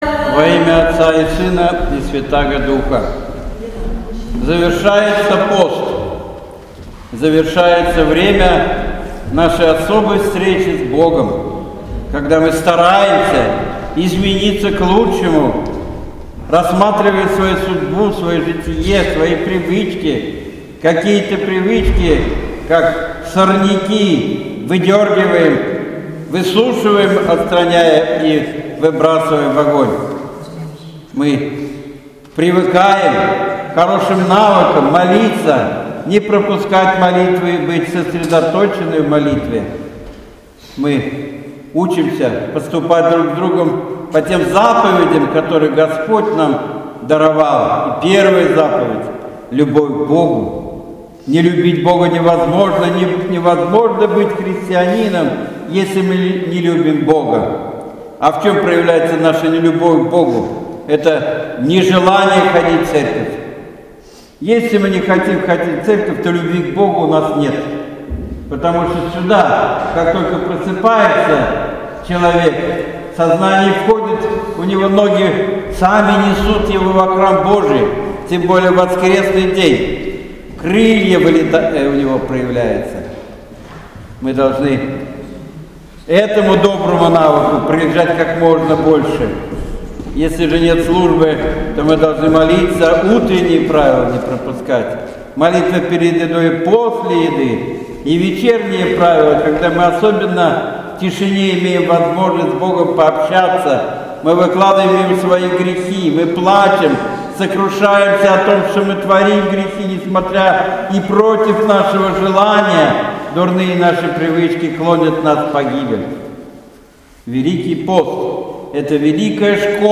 Божественная Литургия 2 апреля 2017 года.